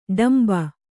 ♪ ḍamba